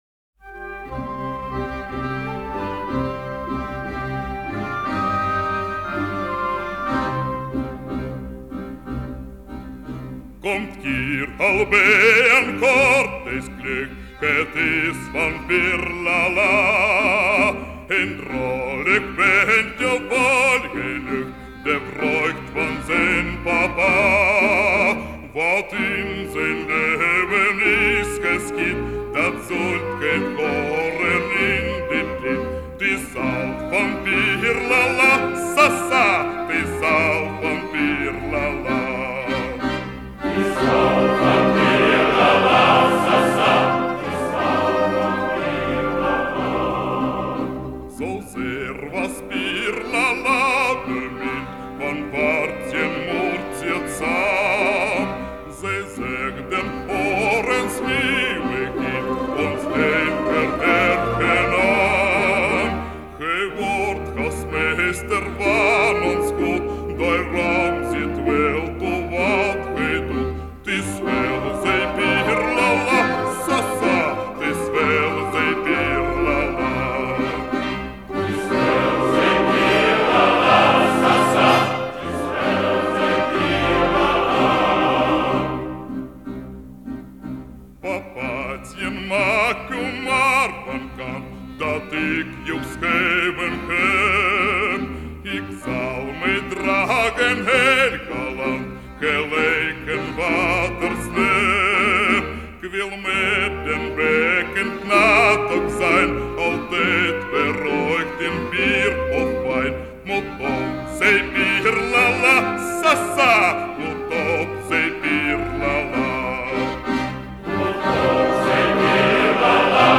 Бельгийская народная песня Исполняет